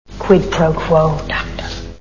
Silence of the Lambs Sound Bites